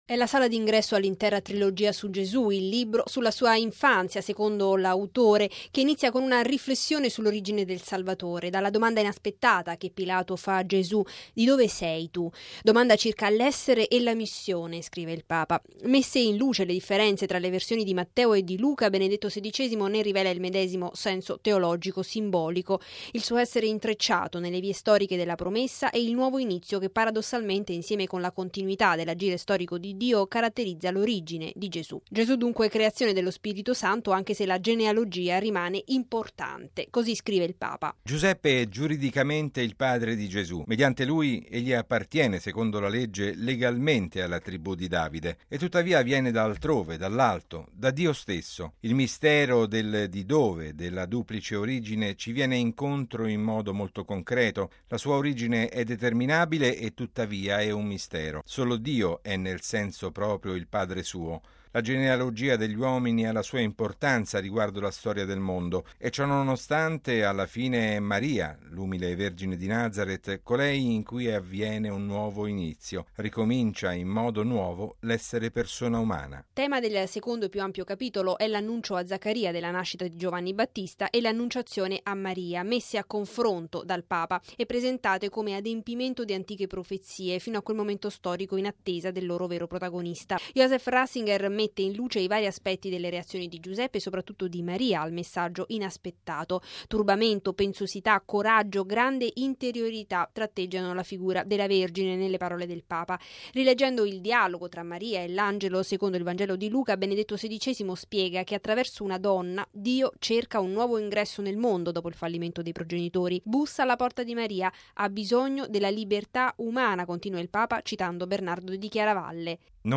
Radiogiornale del 20/11/2012 - Radio Vaticana